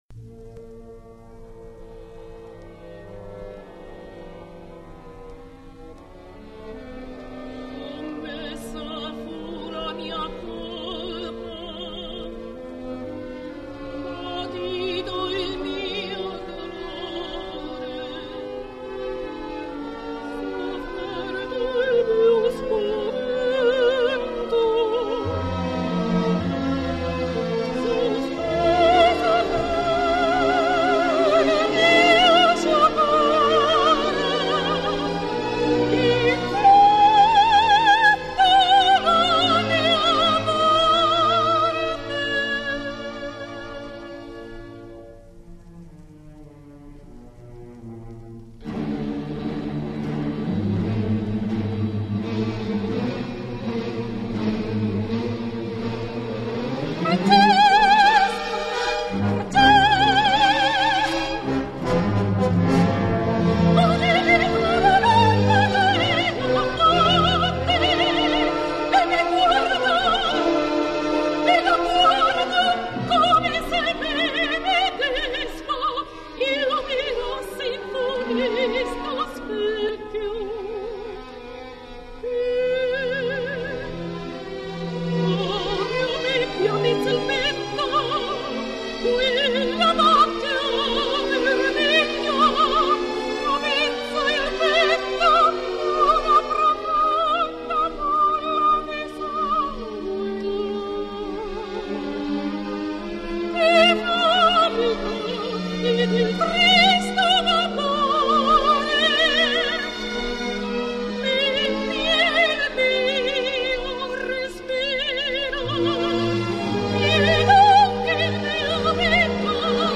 Parisina [Sopran]